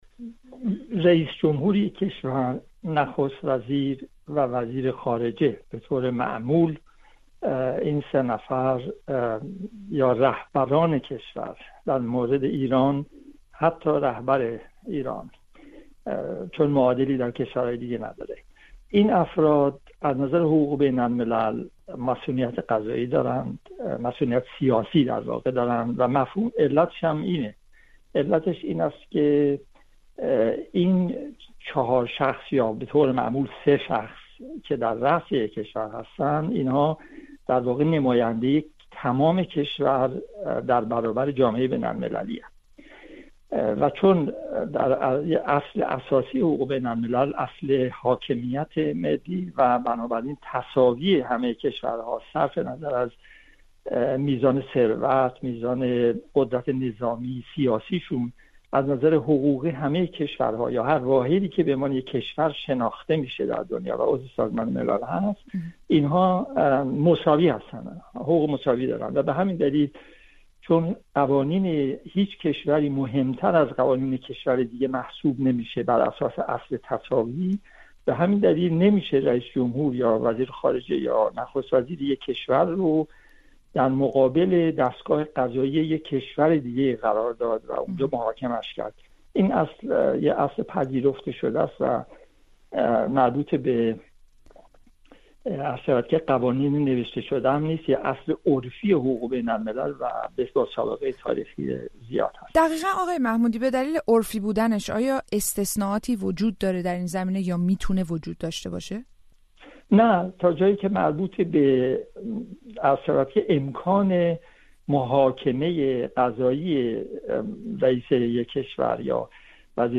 آیا می‌توان ابراهیم رئیسی را به دادگاه کشاند؟ گفت‌وگو